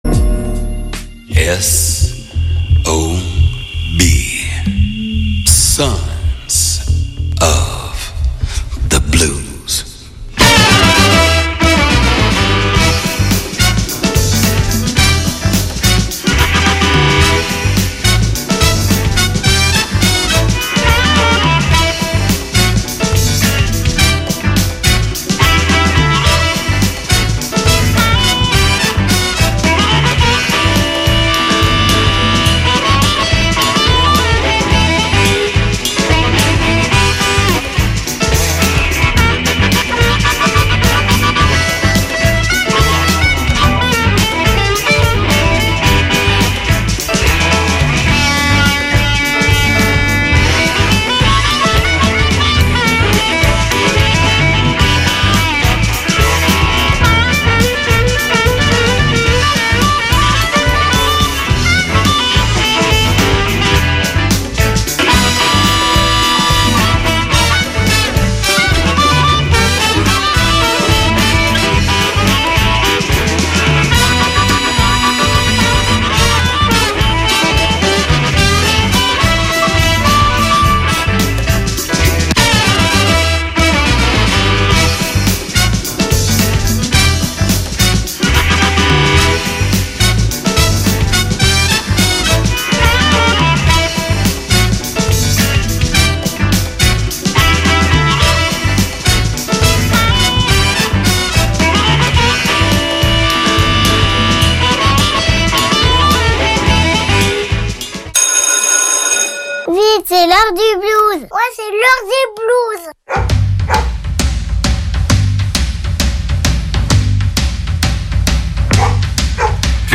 Puisque nous sommes tous des fils et filles du blues, il est bon de se retrouver chaque jeudi à 21H pour 1H de blues d’hier, d’aujourd’hui ou de demain.I